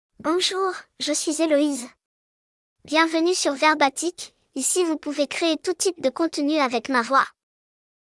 Eloise — Female French (France) AI Voice | TTS, Voice Cloning & Video | Verbatik AI
Eloise is a female AI voice for French (France).
Voice sample
Female
Eloise delivers clear pronunciation with authentic France French intonation, making your content sound professionally produced.